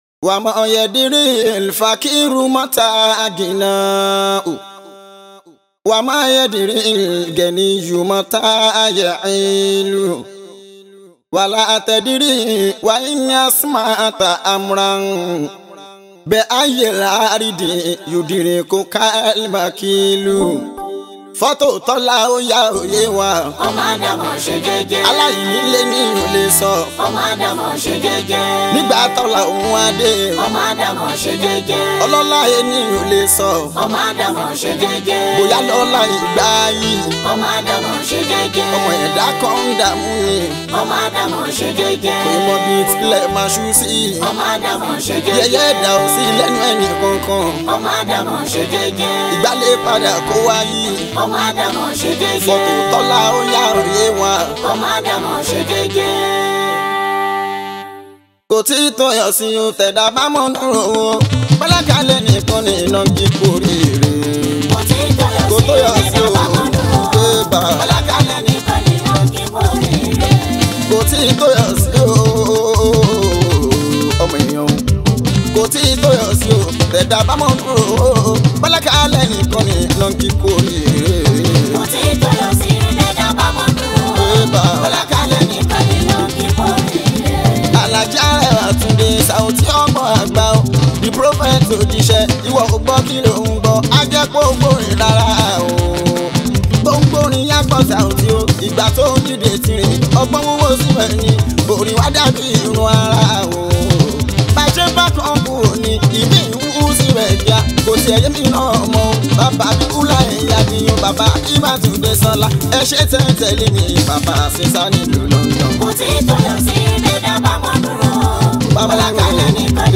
Yoruba Islamic singer
Yoruba Islamic Music